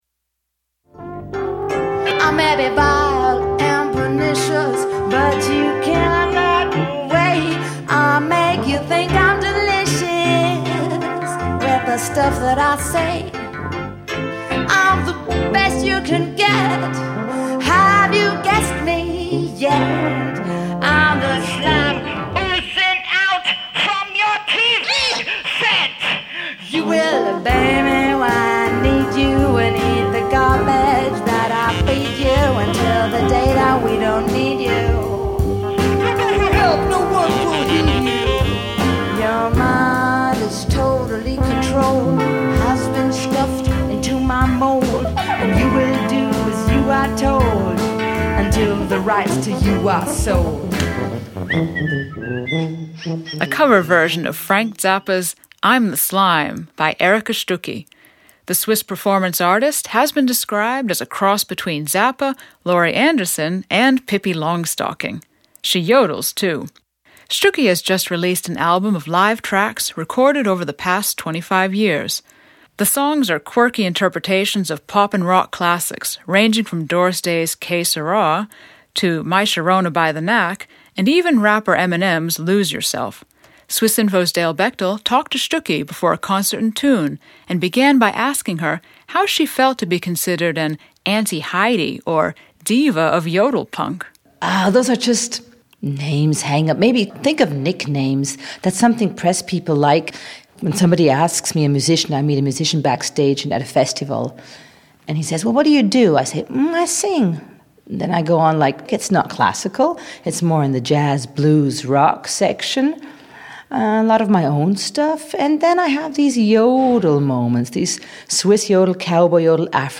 Erika Stucky MP3 file Description woman talking about her music Swiss performance artist Erika Stucky describes her unique approach.